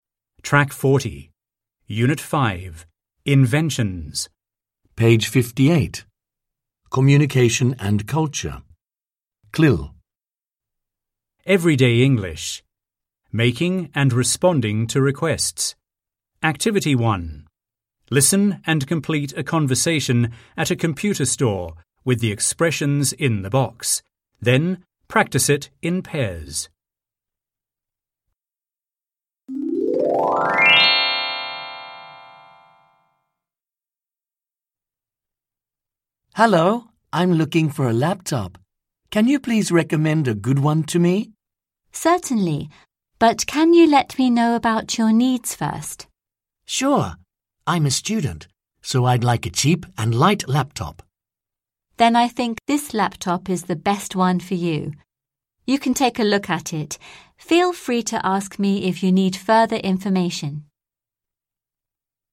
1. Bài tập 1: Listen and complete a conversation at a computer store with the expressions in the box.